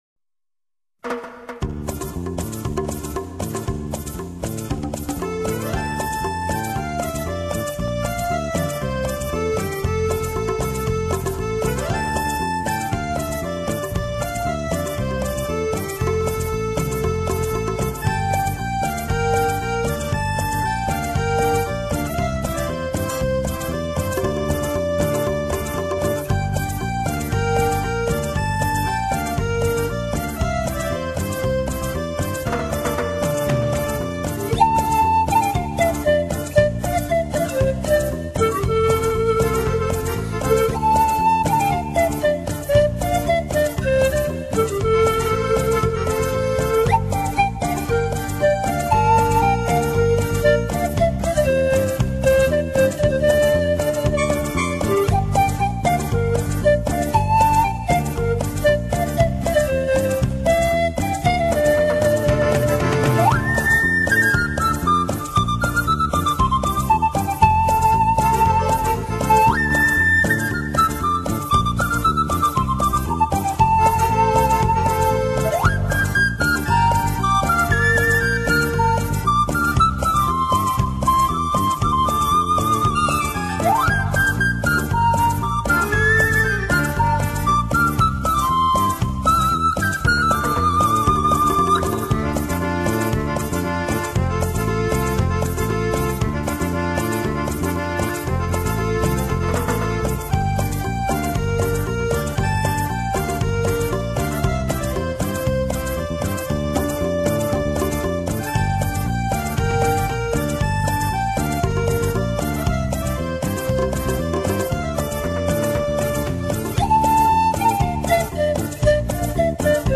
乐器----排 箫 排箫又叫排笛、潘笛，是西洋乐器中最有魅力的乐器之一。
排笛音域宽广，音区变化大，音色丰富。
这样的音 质，适合表现空灵的意境，纷飞的思绪，缥缈的梦境，醇厚的深情。